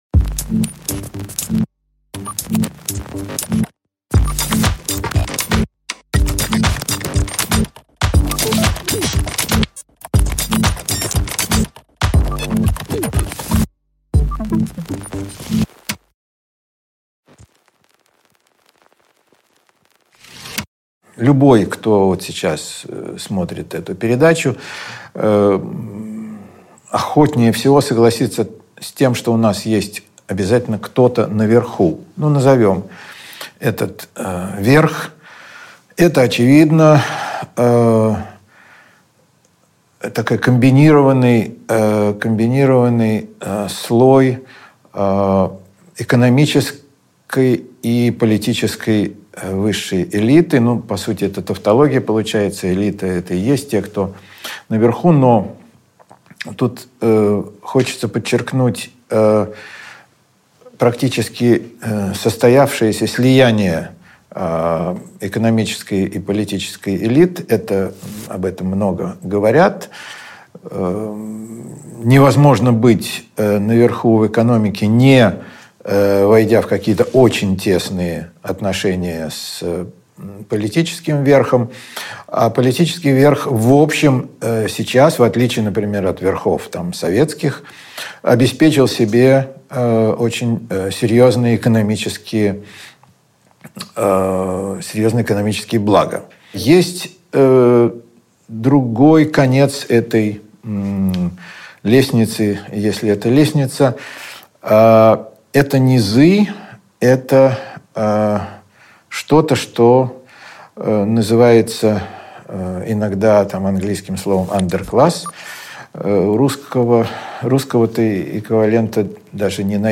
Аудиокнига Строить заново | Библиотека аудиокниг